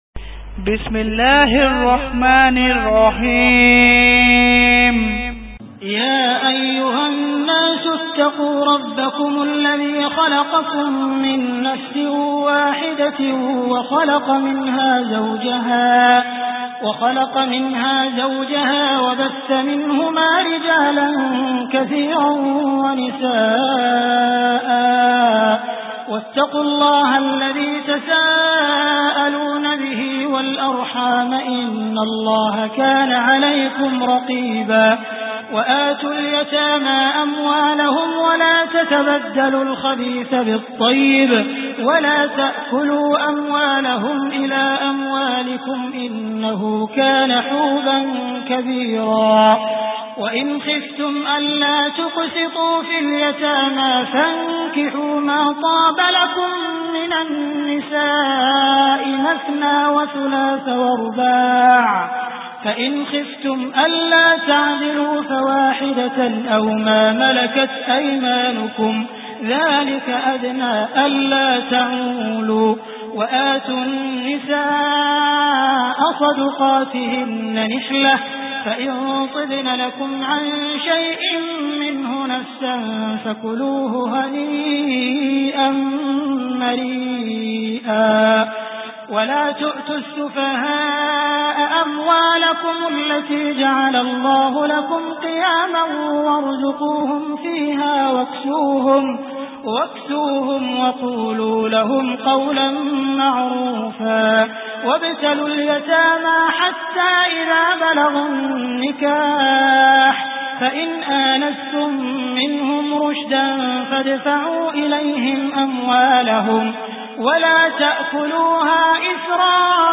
Surah An Nisa MP3 Download By Abdul Rahman Al Sudais. Surah An Nisa Beautiful Recitation MP3 Download By Abdul Rahman Al Sudais in best audio quality.